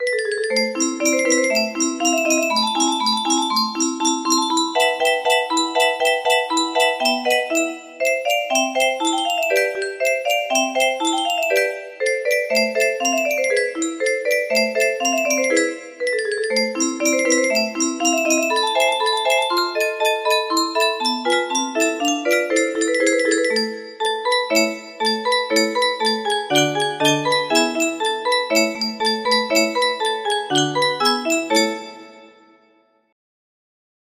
Yay! It looks like this melody can be played offline on a 30 note paper strip music box!
Clone of W. A. Mozart - Rondo Alla Turca(Turkish March) - pts1&2 music box melody We use cookies to give you the best online experience.
BPM 120